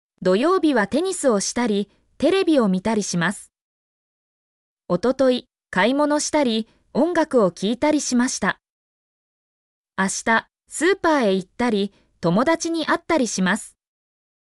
mp3-output-ttsfreedotcom-54_eEp9VK8E.mp3